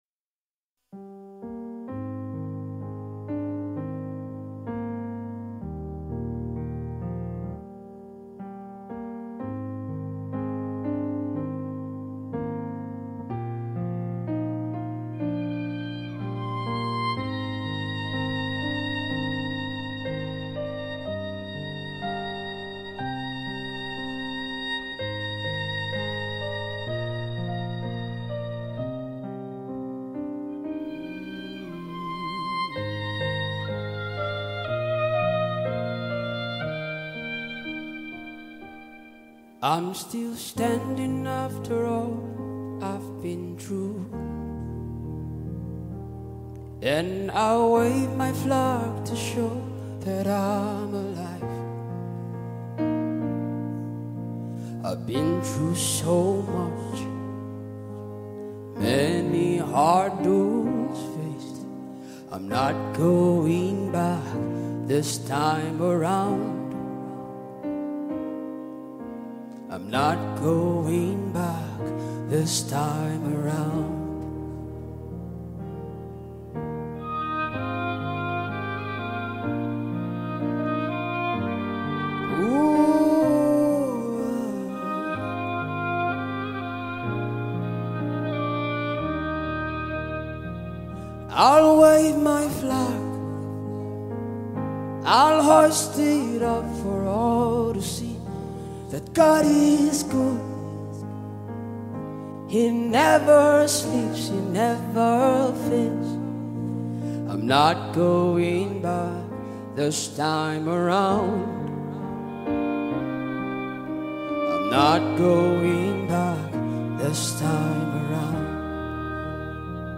A song off his forthcoming Live Album